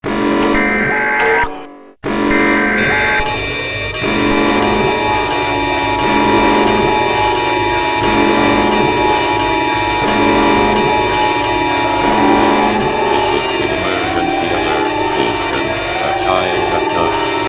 The Usa Eas Alarm (1961) Sound Button - Free Download & Play